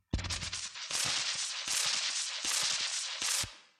vinylnoise1
描述：老乙烯基噪音......
Tag: 记录 乙烯基 裂纹 噪音 表面噪声